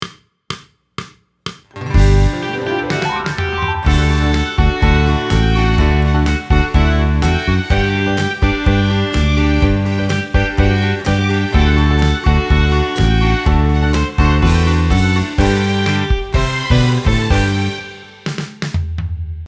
Intro (Dạo đầu):